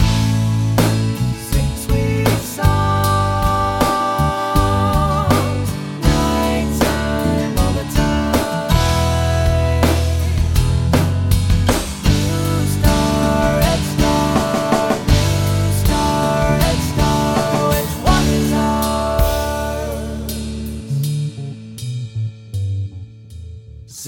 Becuase this chorus is about voices from outer space, I option-dragged the pitch curve totally flat, so all variation was removed.
Finally, I restored the vibrato at the end of each phrase by selecting that part of the pitch curve and pressing Delete.
The quick, robotic snaps between notes are great here, becuase of the song's outer space vibe, and are totally intentional.
Some audio artifacts are easily audible when the voices are soloed, but with the huge wash of reverb from DP's Plate plugin, you can hardly hear the artifacts.
bsrs-pitch.mp3